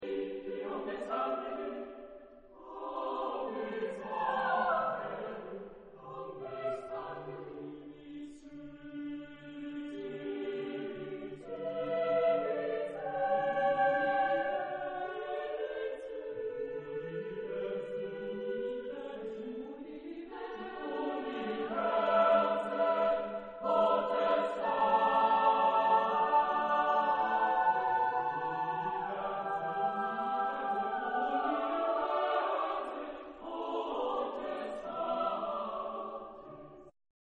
Género/Estilo/Forma: Sagrado ; Romántico ; Extracto de la Misa
Tipo de formación coral: SATB + SATB  (8 voces Doble coro )
Ref. discográfica: Internationaler Kammerchor Wettbewerb Marktoberdorf